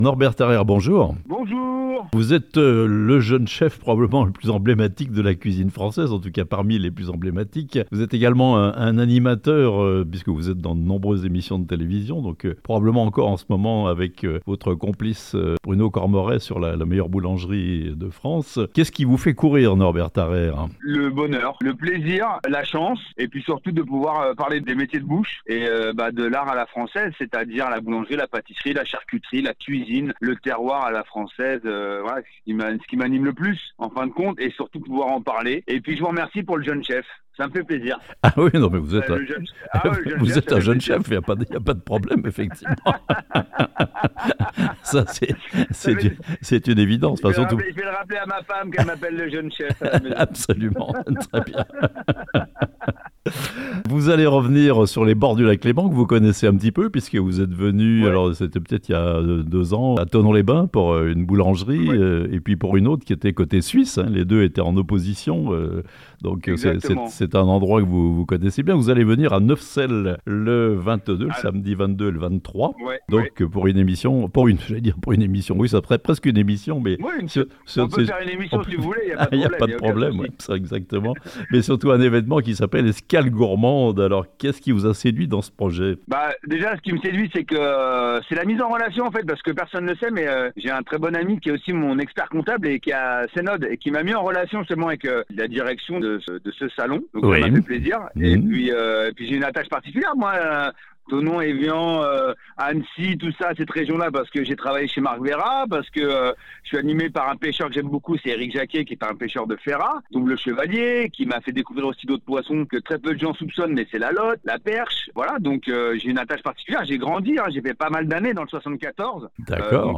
Le chef Norbert Tarayre, invité d'honneur du 1er festival l'Escale Gourmande les 22 et 23 avril à Neuvecelle (interview)